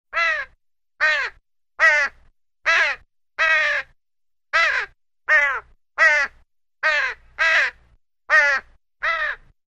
Звуки воронов
На этой странице собраны разнообразные звуки воронов – от резкого карканья до глухого клекота.